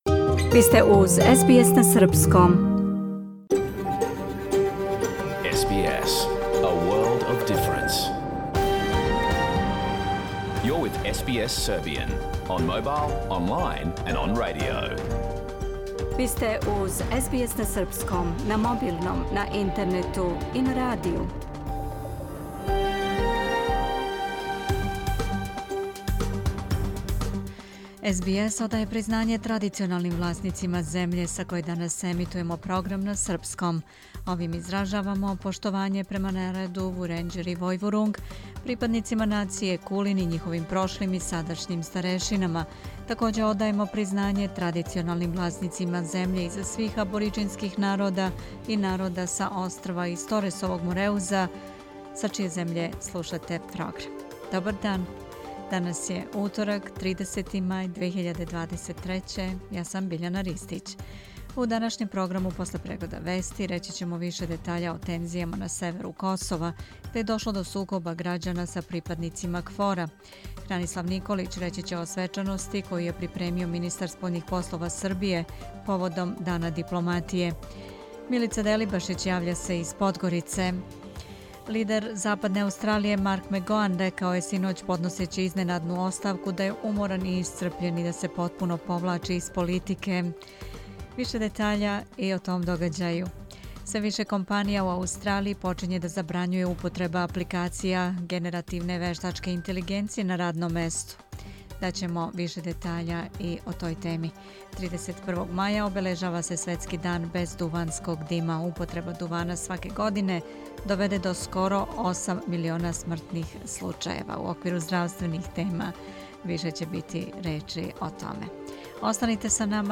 Програм емитован уживо 30. маја 2023. године
Ако сте пропустили данашњу емисију, можете да је слушате у целини као подкаст, без реклама.